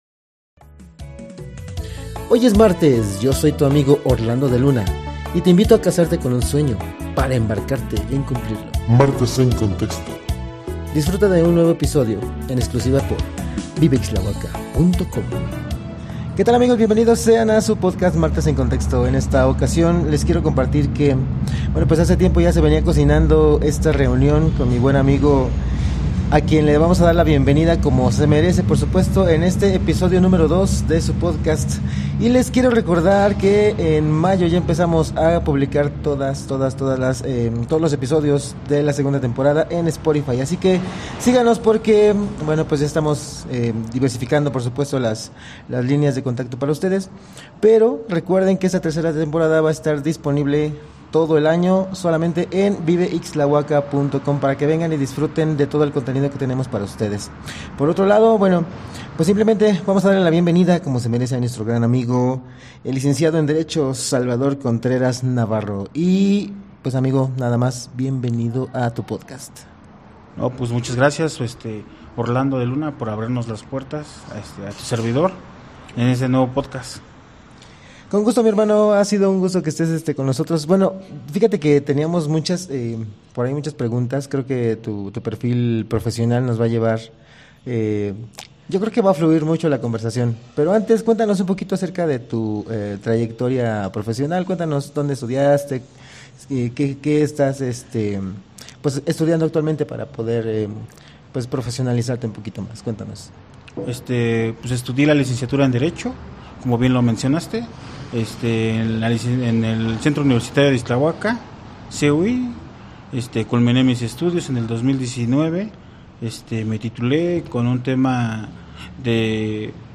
Locación: “El Rincón Texano".